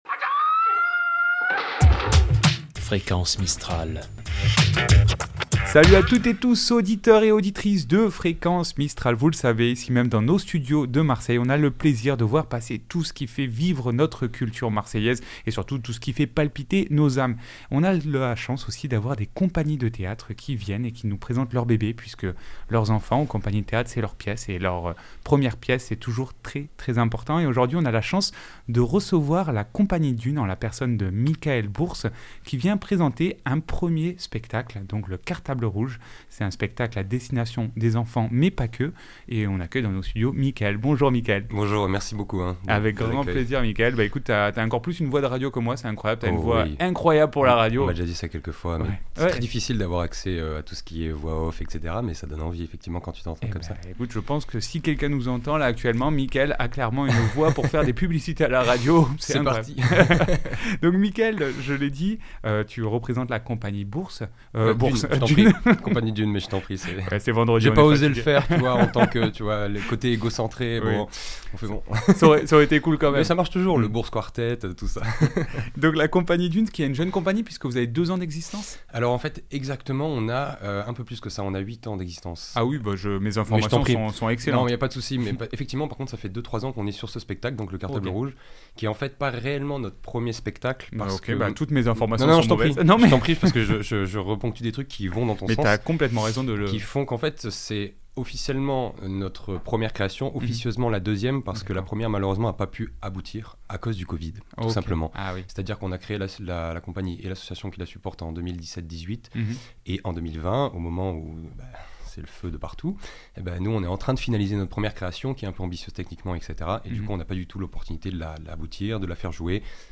Itw Dune cartable rouge .mp3 (16.5 Mo)